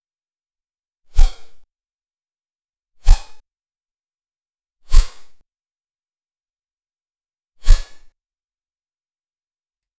Royalty-free Weapons sound effects
An extordinarly heavy sword woosh
an-extordinarly-heavy-swo-cgtclxvw.wav